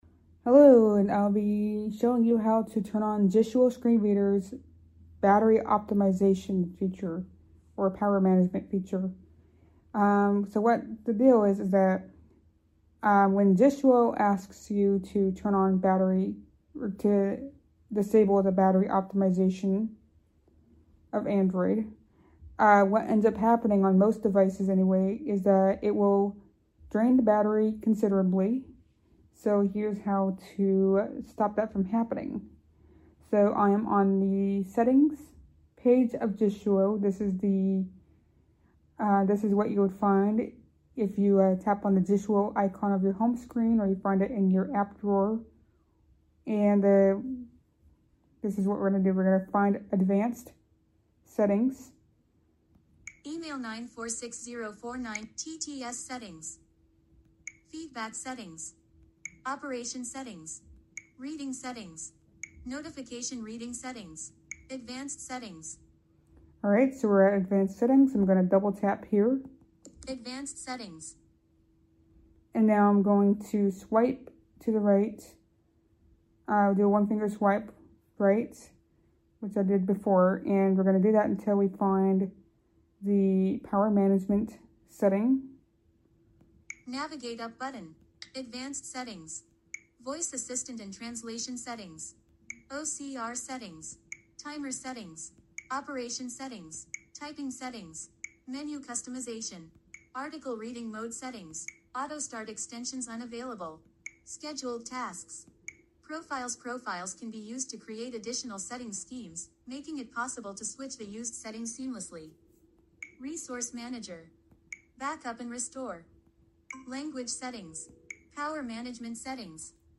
Audio Guide
Those phone speakers were quite good.